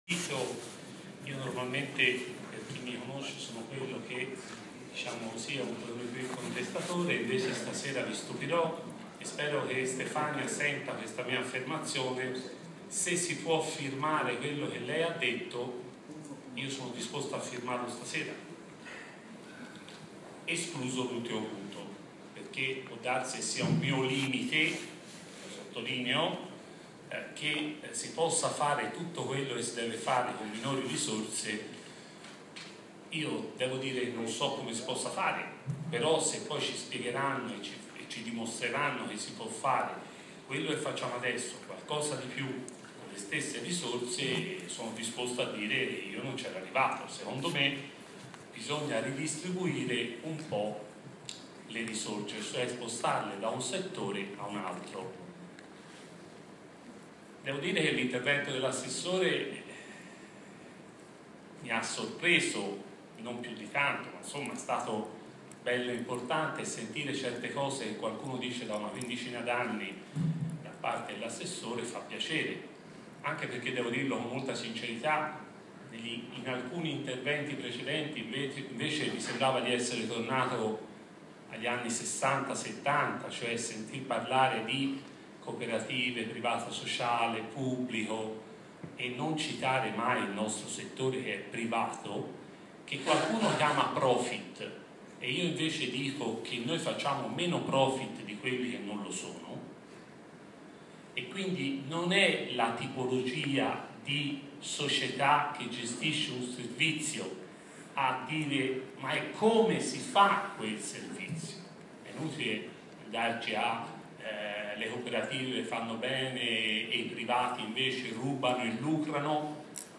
Si è tenuta ieri a Firenze, presso l’Auditorium de La Nazione, la tavola rotonda promossa dal Centro Studi Orsa, la tavola rotonda su interpretazione autentica, semplificazioni e revisioni previste e auspicabili, riguardo al testo dell’ultima delibera regionale in materia di assistenza sociosanitaria.
Ne riportiamo fedelmente gli interventi registrati, scaricabili e ascoltabili in formato mp3: